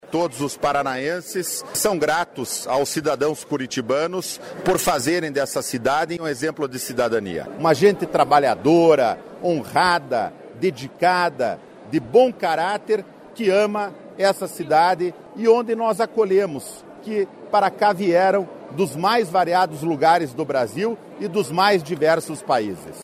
Ouça entrevista do parlamentar, que promoveu sessão solene no Plenário da Assembleia nesta quarta-feira (28) antecipando a comemoração ao aniversário da capital, celebrado em 29 de março.
(sonora)